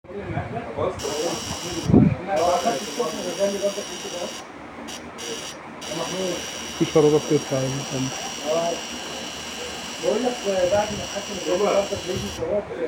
Emits steady 2.3 kHz tone at 80 dB.
The metal enclosure amplifies and directs the sound, providing a piercing beep at around 2.3 kHz.
• Generates a steady tone around 2.3 kHz.
Buzzer Sound: